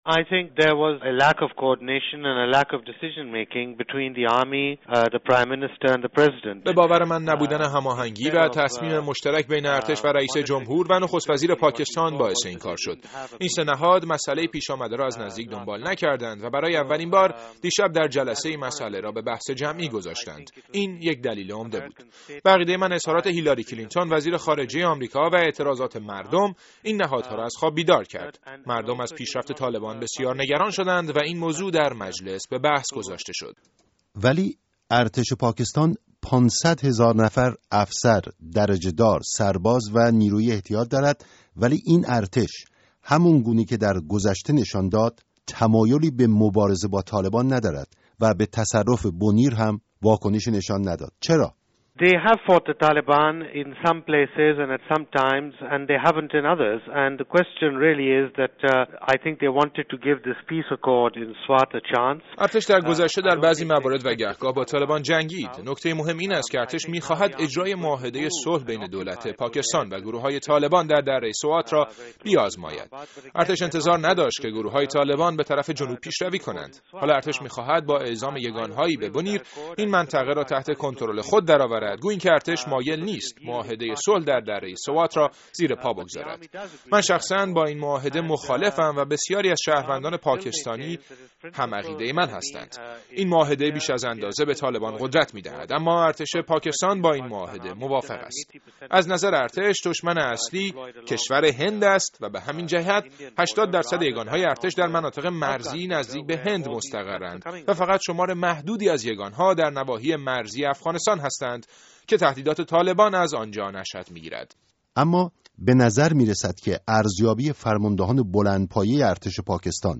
گفت‌وگو با احمد رشید، تحلیلگر مسائل پاکستان